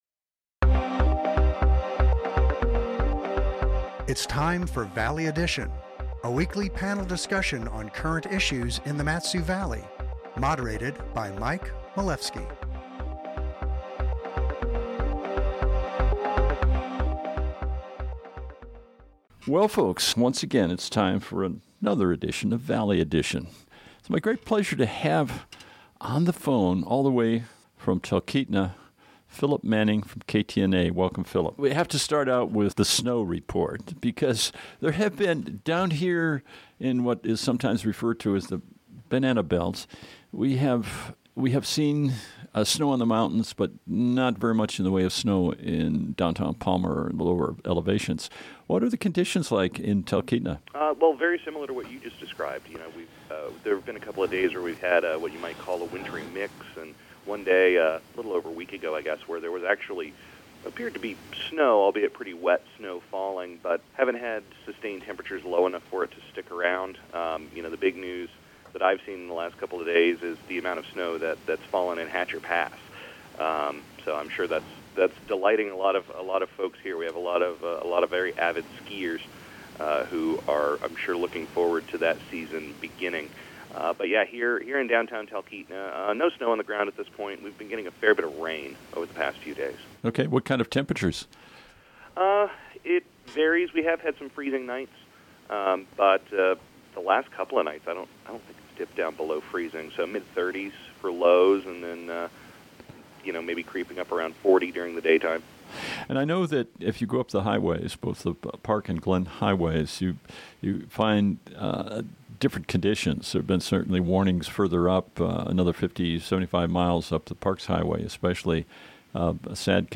moderates a panel on current issues in the Valley